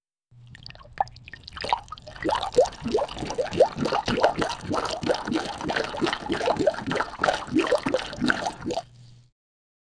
Water splashing against a lemonade can in slow motion. High-quality, ear-pleasing sound for editing.
water-splashing-against-a-zlhia7eu.wav